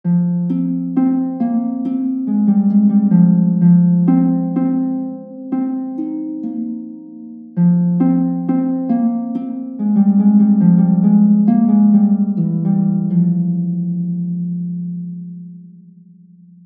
Harfa
Dźwięk wydobywa się szarpiąc struny.
Dźwięki instrumentów są brzmieniem orientacyjnym, wygenerowanym w programach:
Harfa.mp3